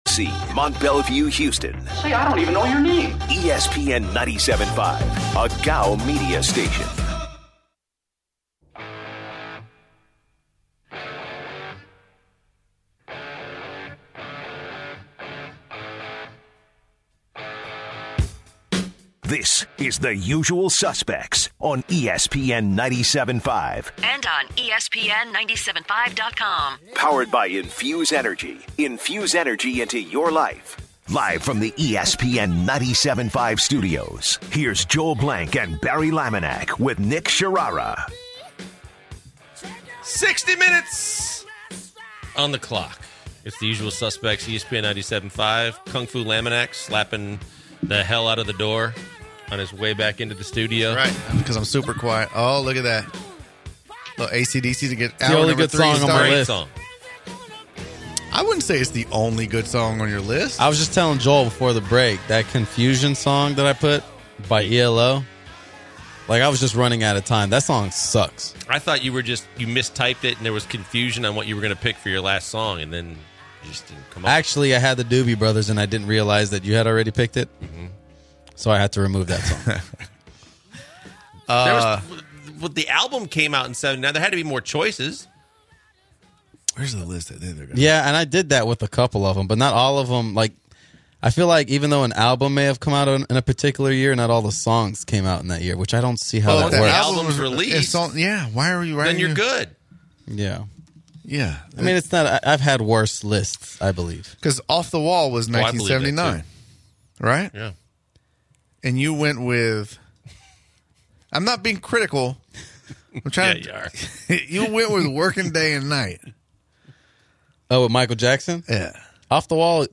The guys take a call to talk about the NBA Draft and what New Orleans could do now that they have the leverage with the #1 pick and the possibility to trade Anthony Davis. They close the show with discussion on other possible NBA trades that could happen this offseason.